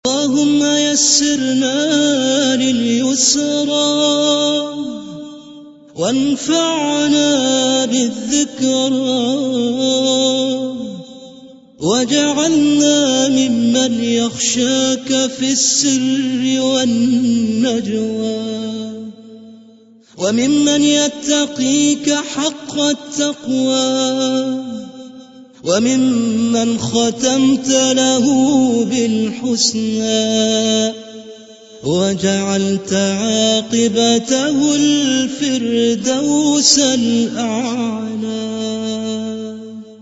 ادعية